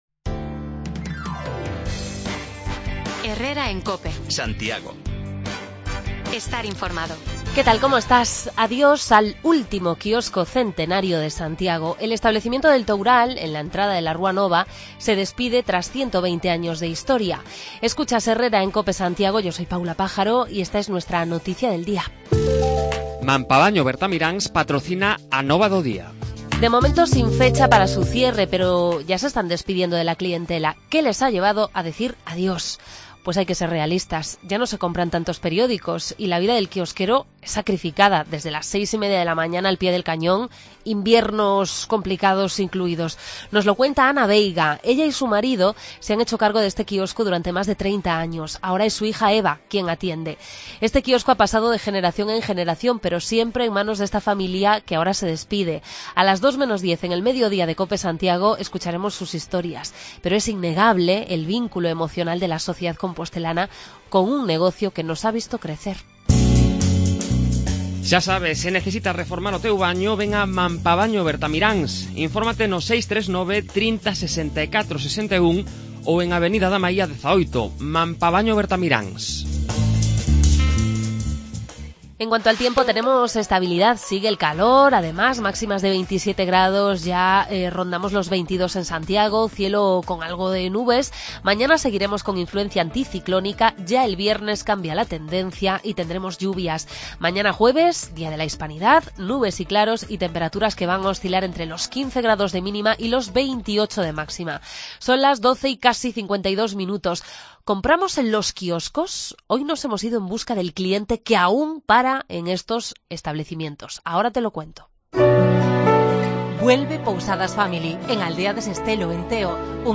En la cuenta atrás para el cierre del kiosko del Toural, el último centenario que nos queda en la ciudad, bajamos el micro de Cope Santiago a la calle para saber si compramos en estos establecimientos. Recogemos también la queja de un usuario del transporte público en Compostela, reclamando más refuerzos en la línea 6.